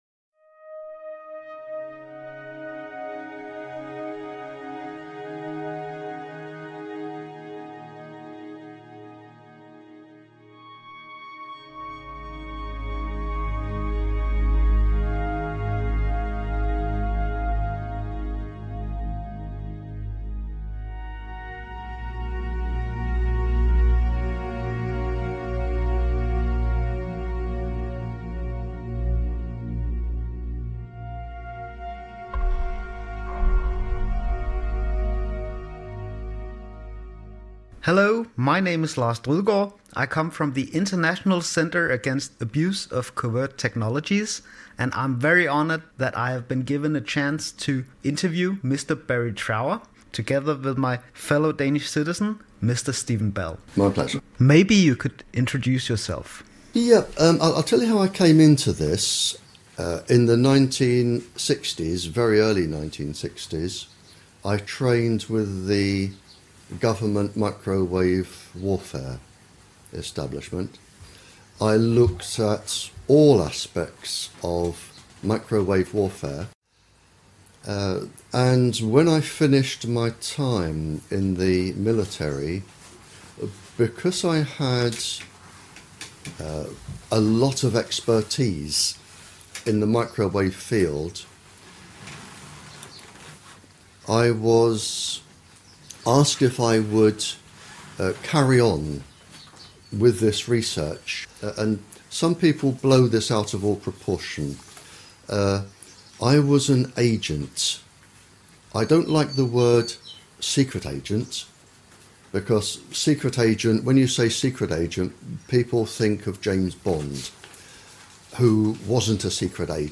in a sit down interview